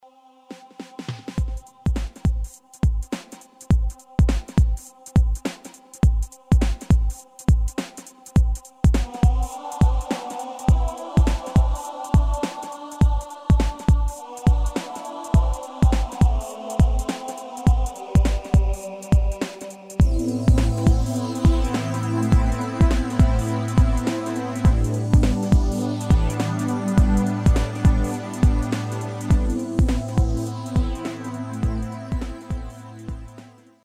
Demo/Koop midifile
Taal uitvoering: Instrumentaal
Genre: Pop & Rock Internationaal
Originele song is instrumentaal